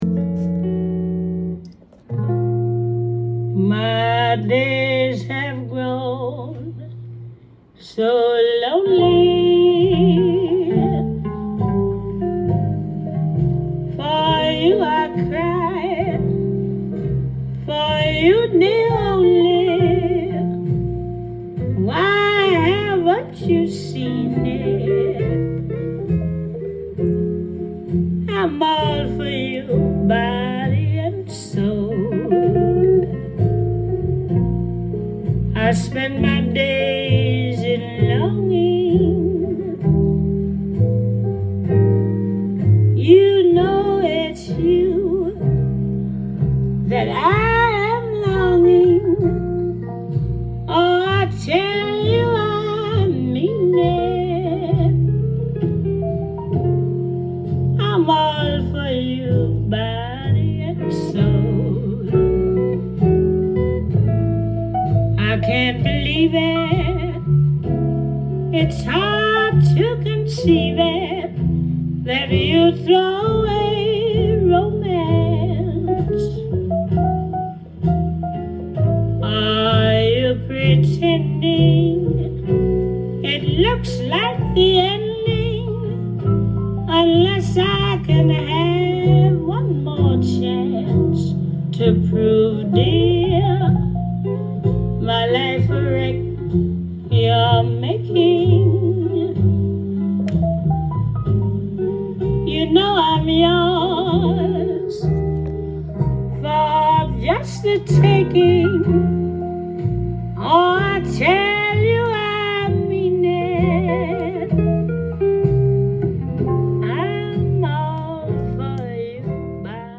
ジャンル：JAZZ-VOCAL
店頭で録音した音源の為、多少の外部音や音質の悪さはございますが、サンプルとしてご視聴ください。
壮絶な人生を経て獲得された、豊かな表現力と凄みを増した歌声に震える晩年の傑作！！